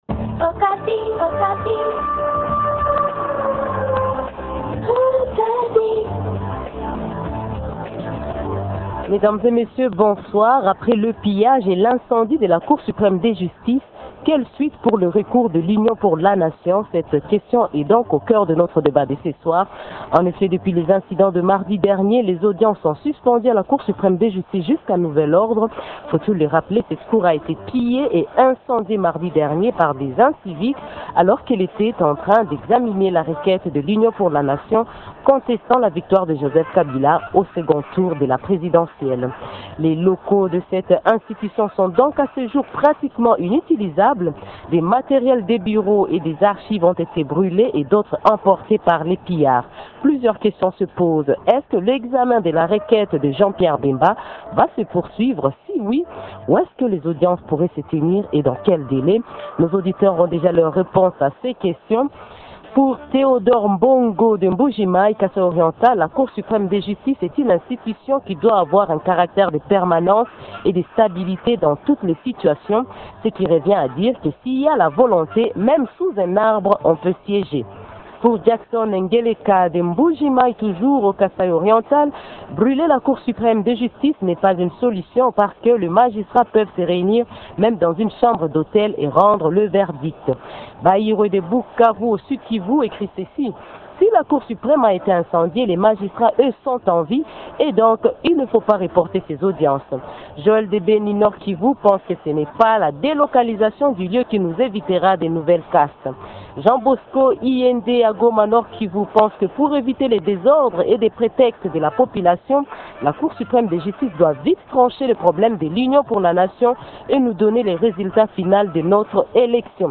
Deux questions sont au centre de notre débat de ce soir, à savoir :rn- Où vont se tenir les audiences ?